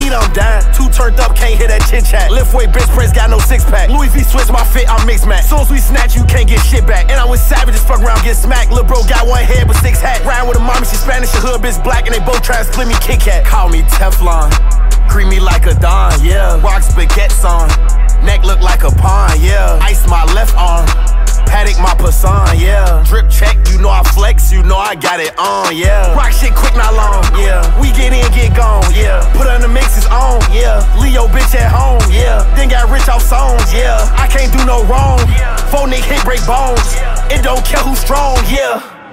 Kategorie Rap